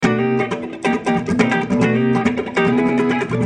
Free MP3 funk music guitars loops & sounds 3
guitar loop - funk 41